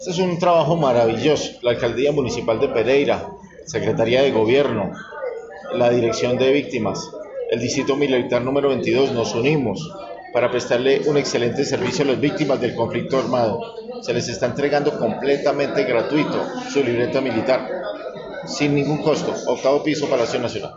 Audio-secretario-de-gobierno-Jorge-Mario-Trejos-Arias-Libreta-Militar.mp3